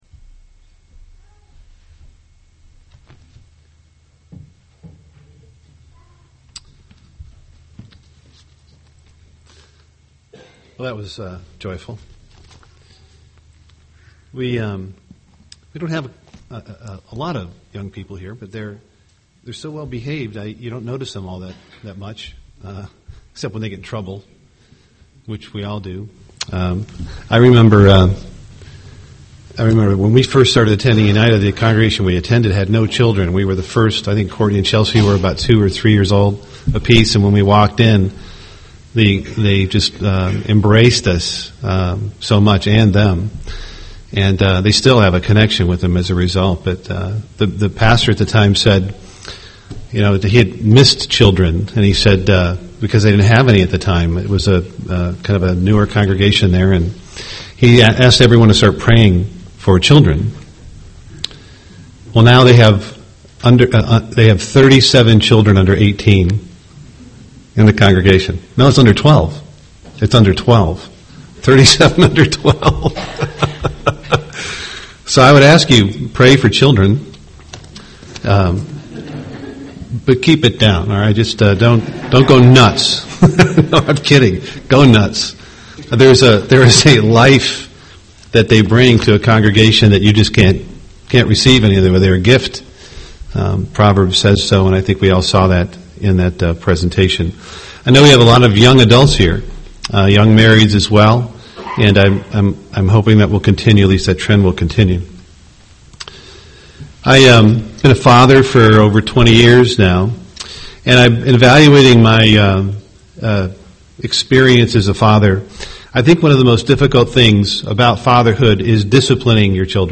Print As a man chastens his son, so the eternal, our loving God and heavenly Father chastens us (Deut 8:5) UCG Sermon Studying the bible?